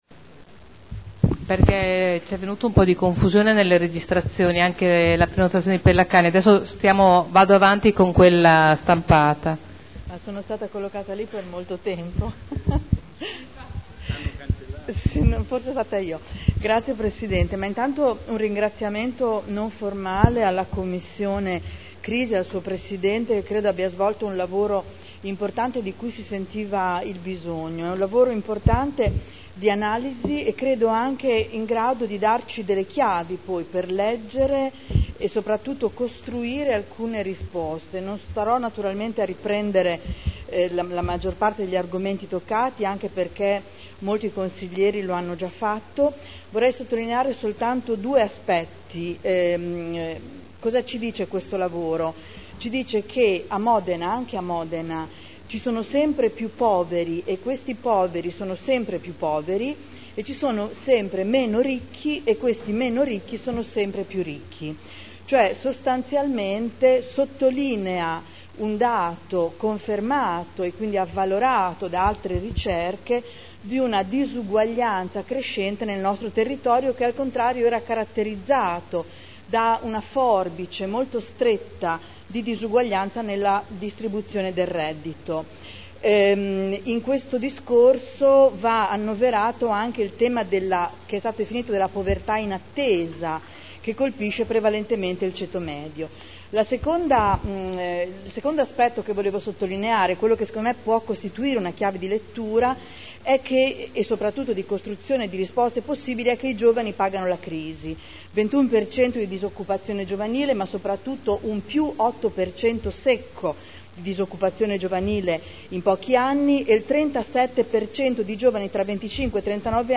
Adriana Querzè — Sito Audio Consiglio Comunale